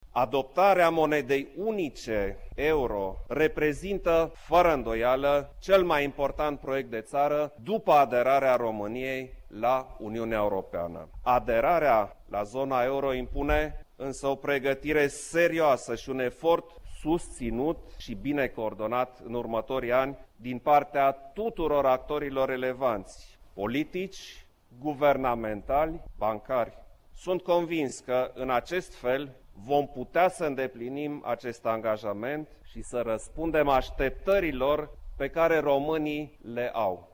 A afirmat, astăzi, Preşedintele Klaus Iohannis, în deschiderea recepţiei pe care o găzduieşte la Palatul Cotroceni, de Ziua Europei.